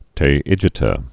(tā-ĭjĭ-tə)